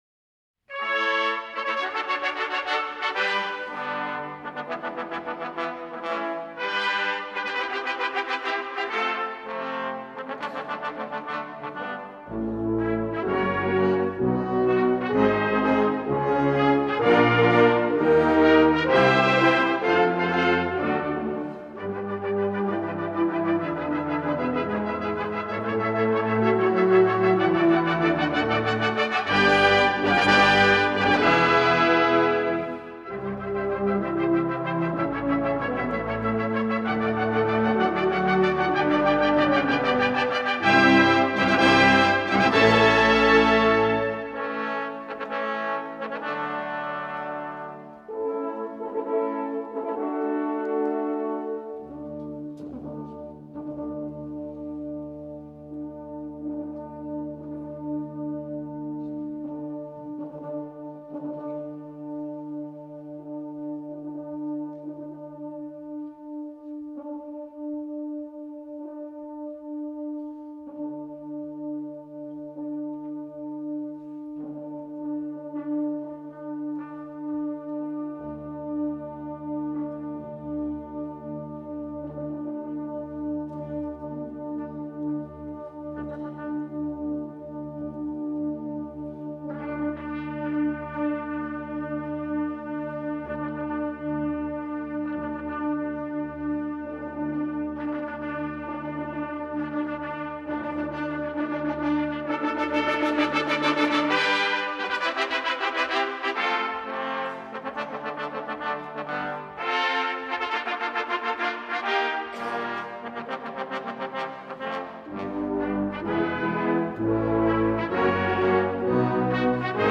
Sinfonie